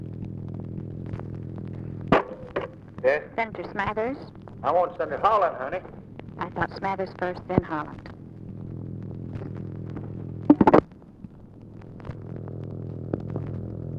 Conversation with OFFICE SECRETARY, November 27, 1963
Secret White House Tapes